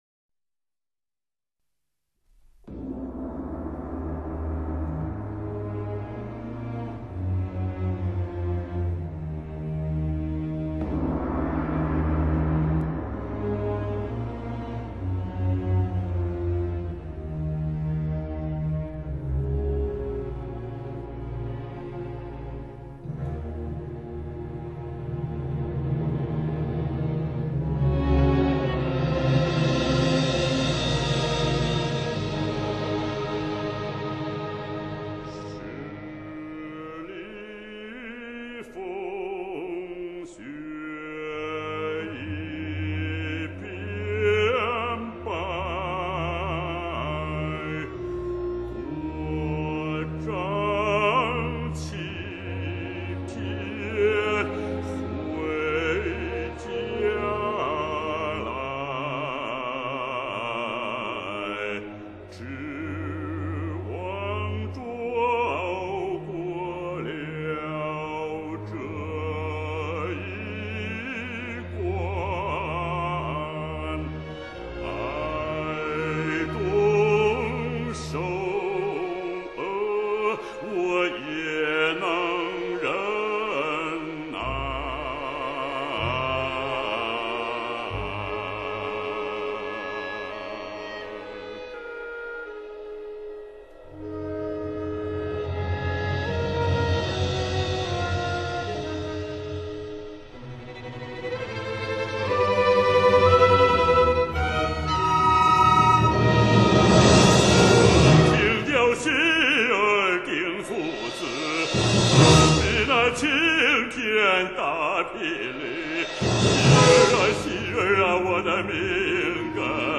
男低音歌唱家